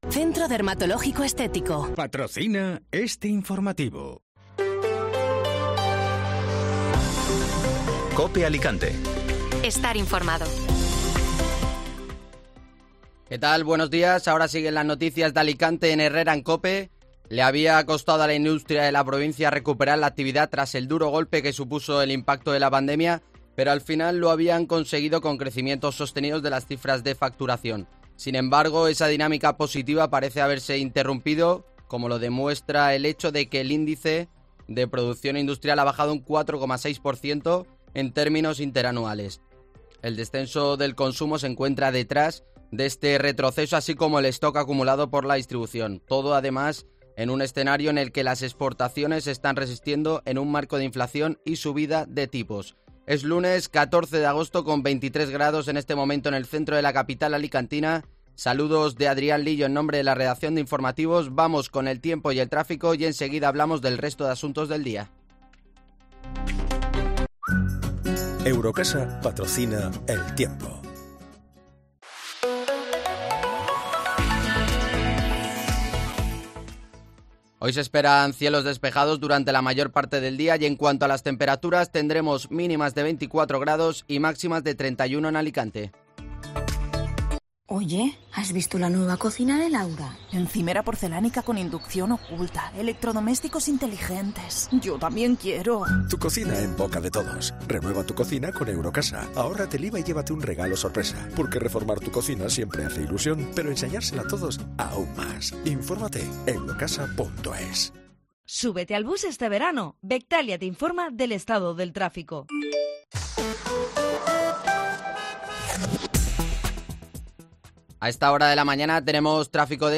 Informativo Matinal (Lunes 14 de Agosto)